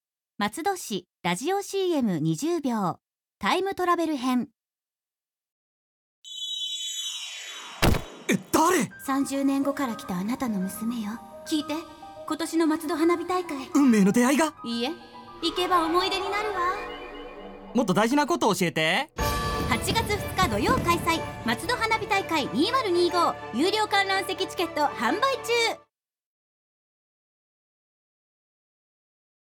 CM収録の様子
ラジオCM放送概要